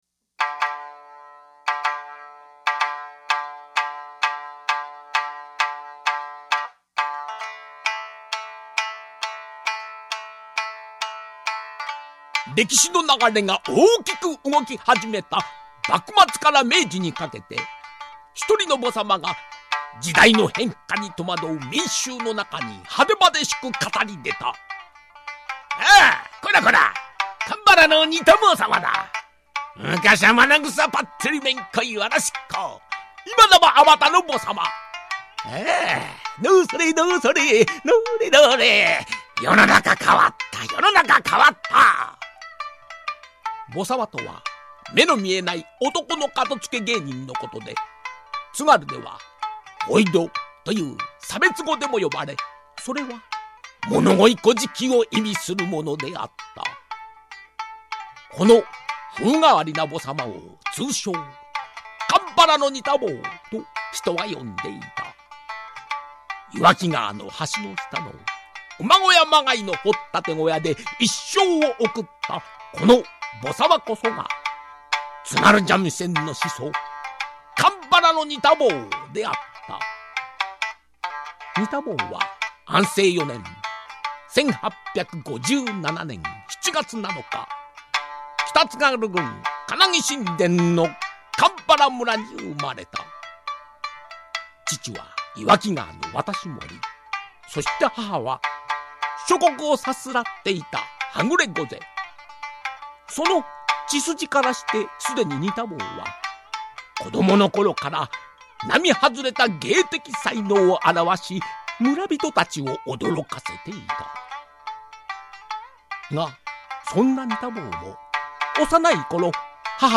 台本制作＆語り＆演奏
平成１２年１月静岡市グランシップにて